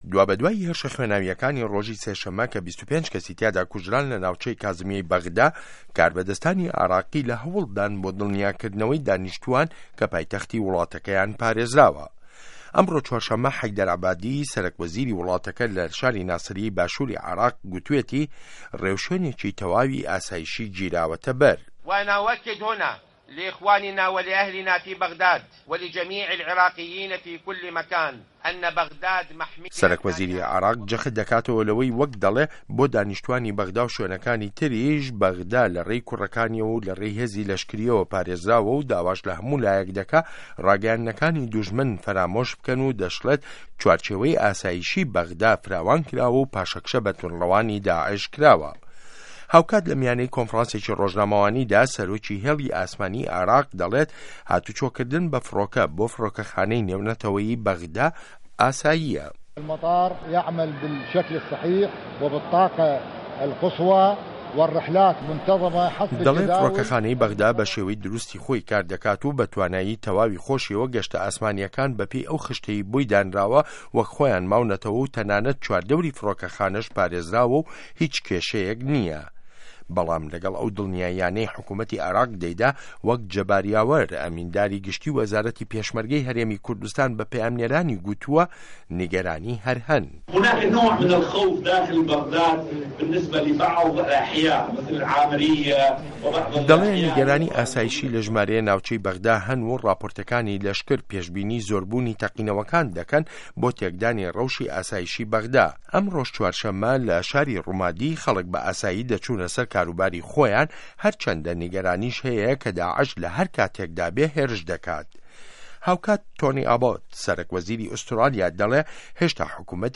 ڕاپـۆرتی عێراق